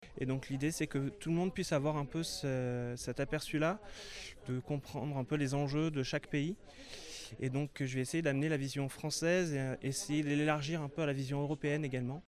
Plusieurs étaient présents lors d’une conférence de presse de lundi au vignoble le Clos de l’île à Saint-Angèle-de-Laval.